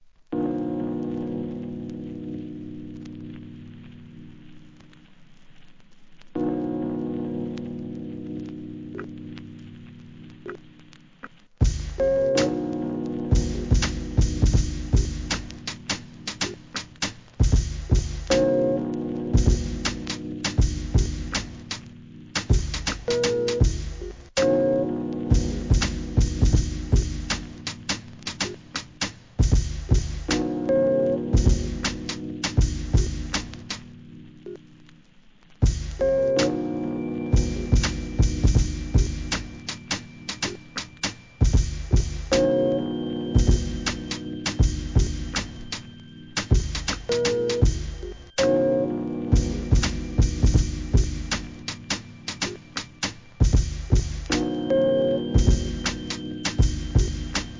HIP HOP/R&B
アブストラクトなBREAK BEATSに擦りネタ等収録のDJ TOOL!!!